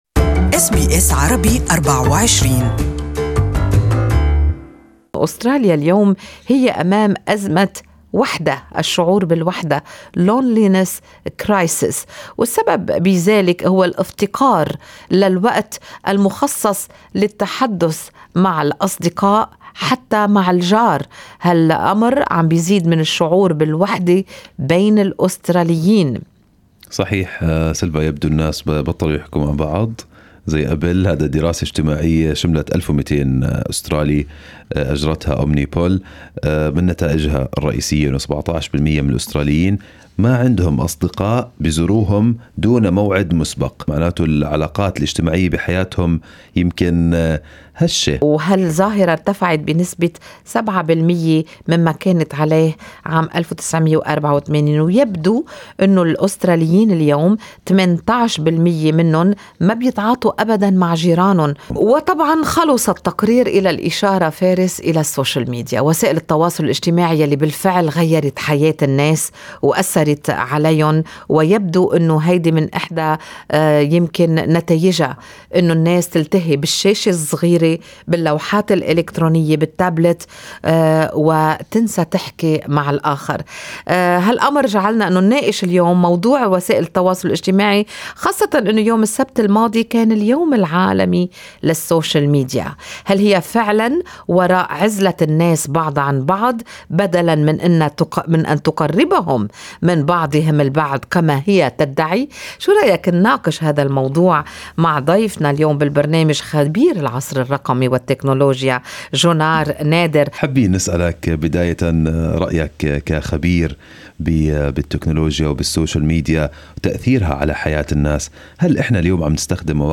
المقابلة كاملة ملحقة بالصورة أعلاه.